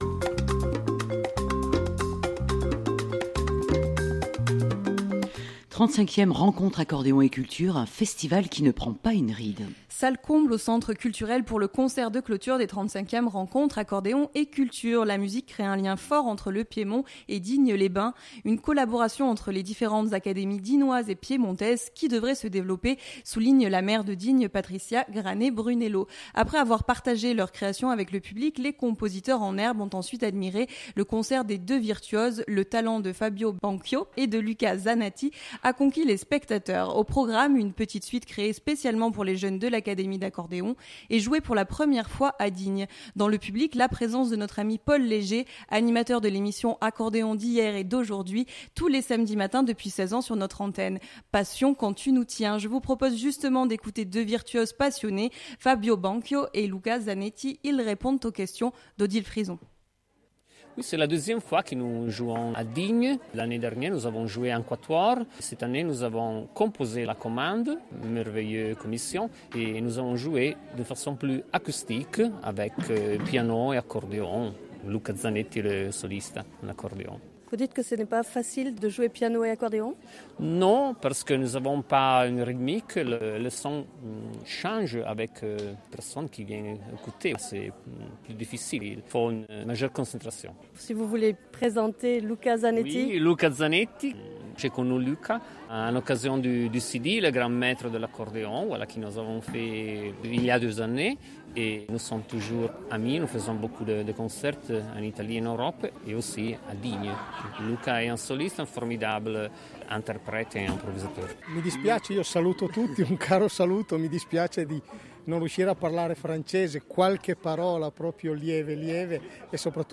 Salle comble au Centre culturel pour le concert de clôture des 35èmes Rencontres Accordéon et Cultures.